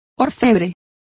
Complete with pronunciation of the translation of goldsmith.